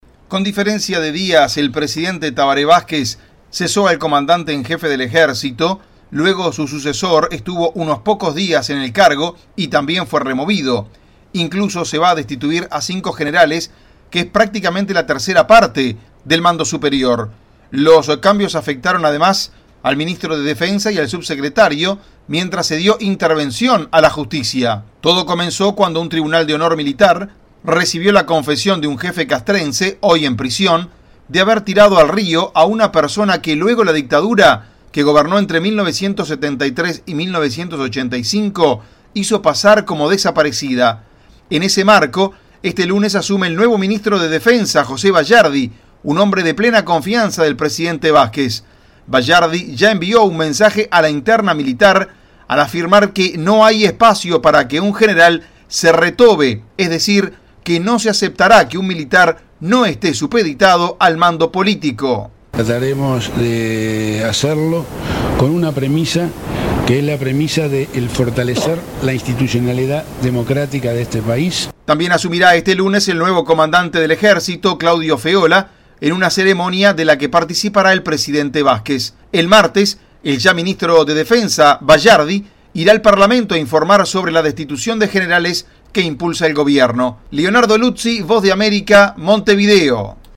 VOA: Informe desde Uruguay